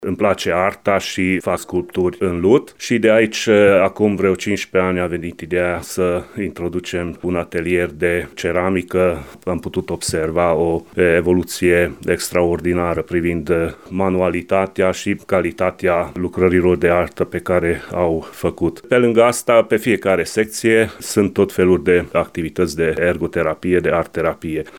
Medic generalist